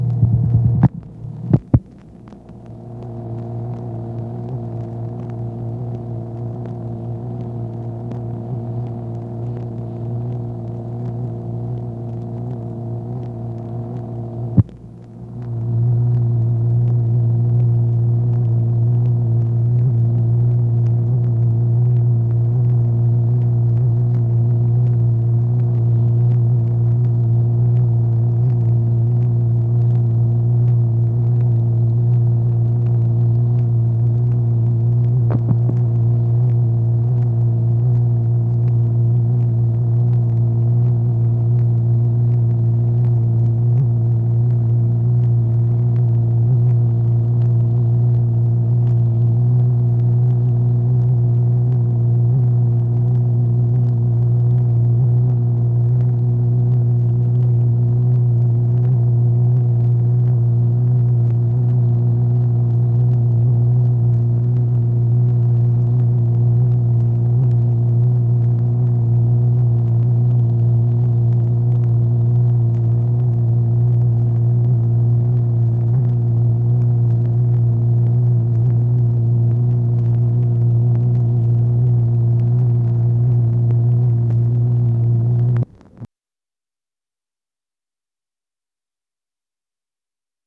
MACHINE NOISE, May 20, 1965
Secret White House Tapes | Lyndon B. Johnson Presidency